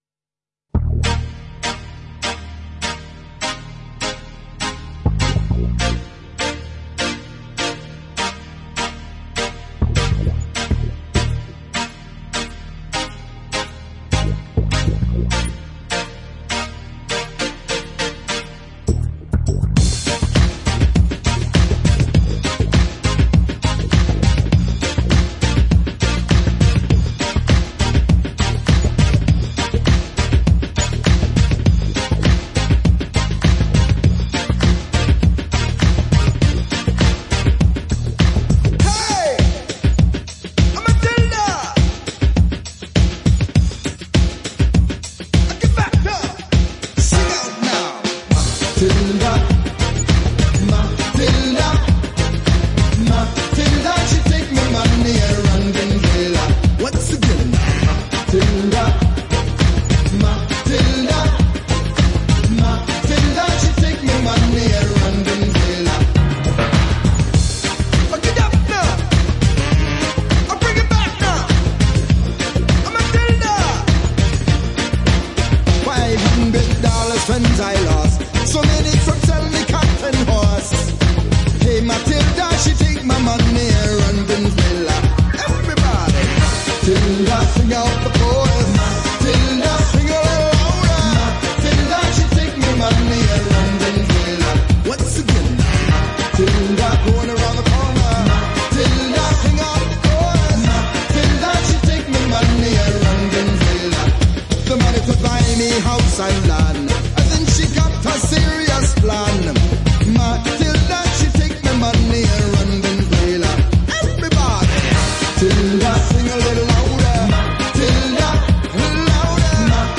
Background vocals